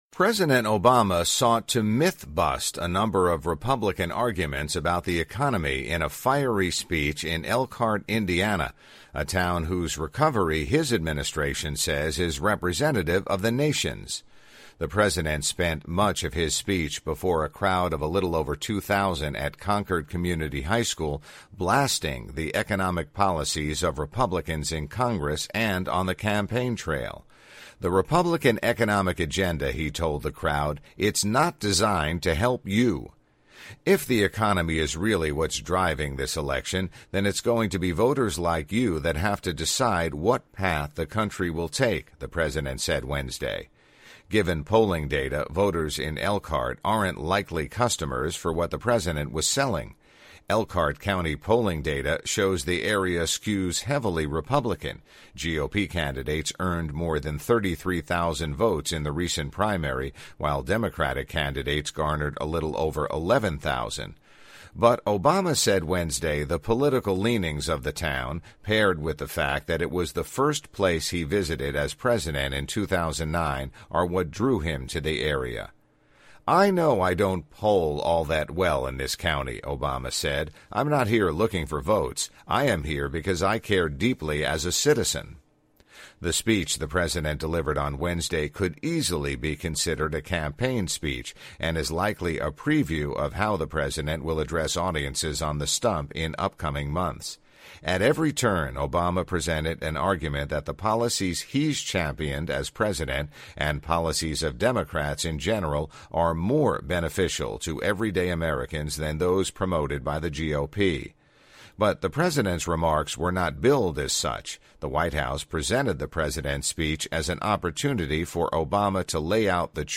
President Obama sought to "myth-bust" a number of Republican arguments about the economy in a fiery speech in Elkhart, Ind., a town whose recovery his administration says is representative of the nation's.
The president spent much of his speech before a crowd of a little over 2,000 at Concord Community High School blasting the economic policies of Republicans in Congress and on the campaign trail.